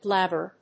音節blab・ber 発音記号・読み方
/blˈæbɚ(米国英語), ˈblæbɜ:(英国英語)/